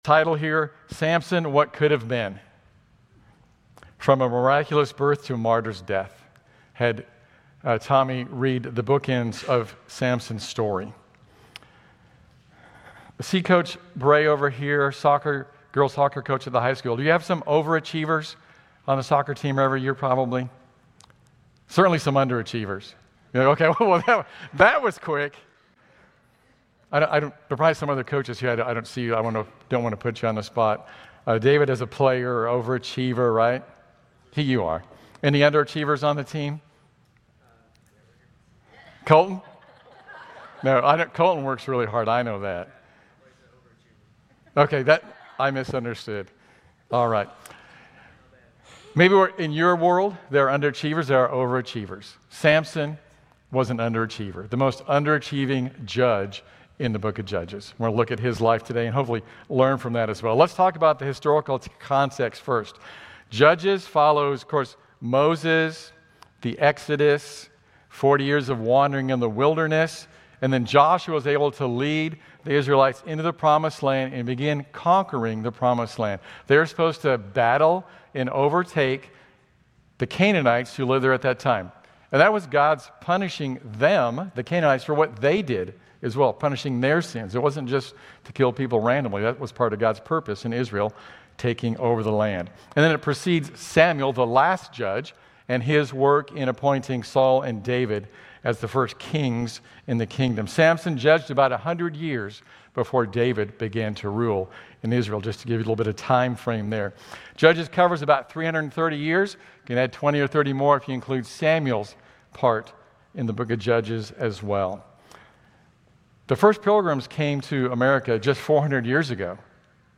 Grace Community Church Lindale Campus Sermons 7_27 Lindale campus Jul 28 2025 | 00:31:23 1x 00:00 / 00:31:23 Subscribe Share RSS Feed Share Link Embed